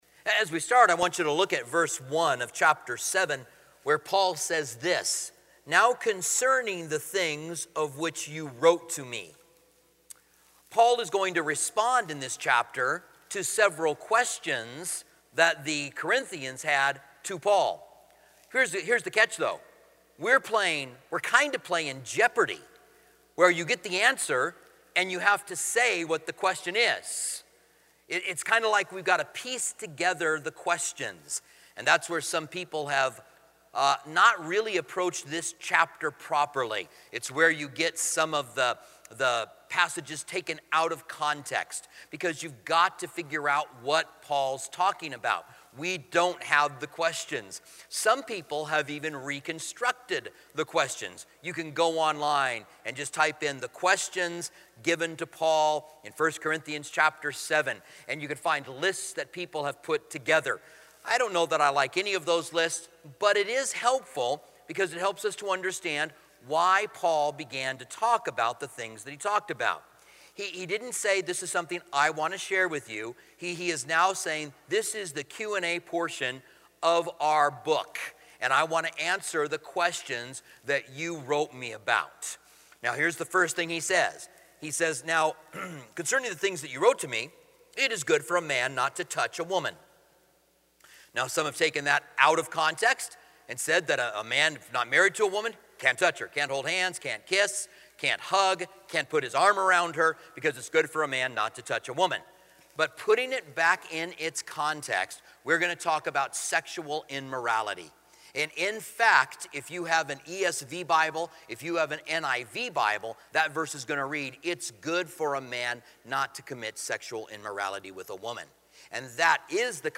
Commentary on 1 Corinthians